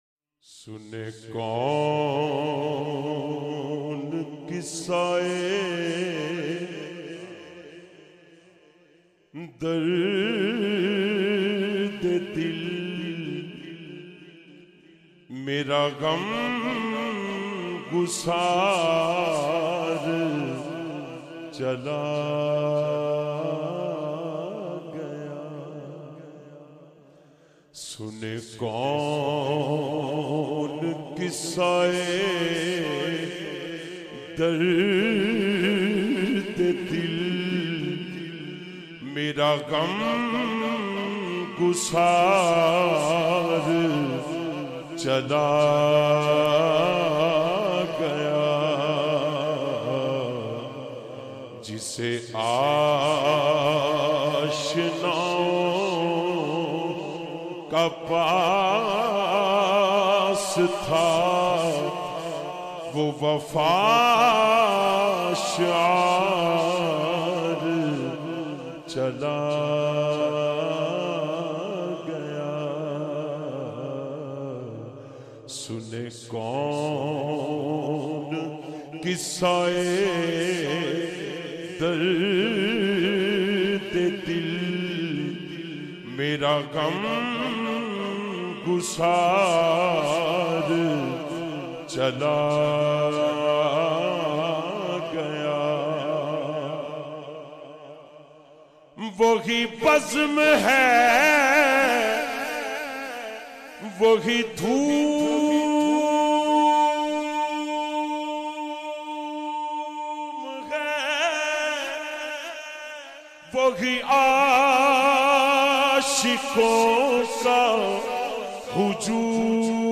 {Slow+Reverb}{Only∆naat}